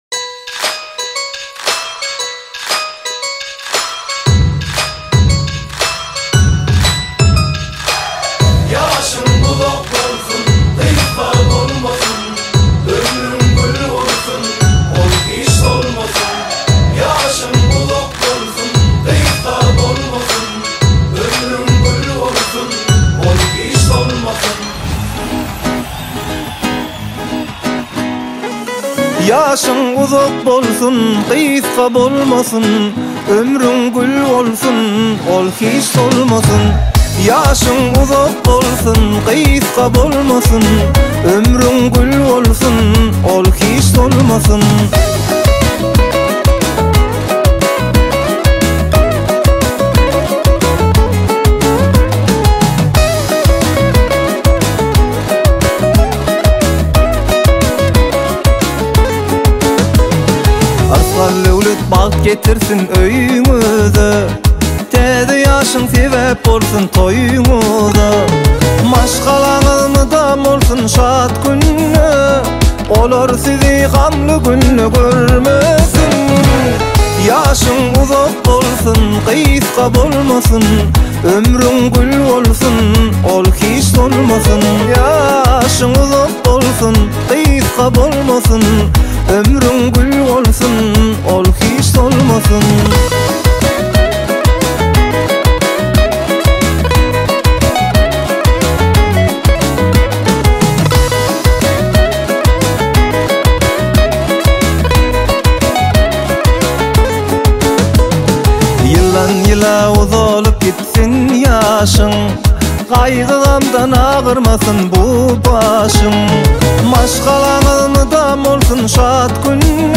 Узбекские песни Слушали